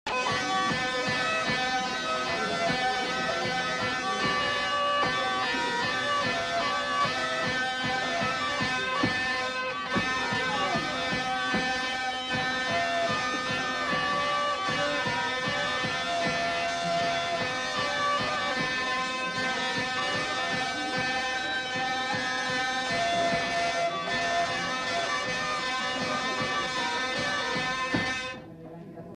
Aire culturelle : Gabardan
Lieu : Gabarret
Genre : morceau instrumental
Instrument de musique : vielle à roue
Danse : mazurka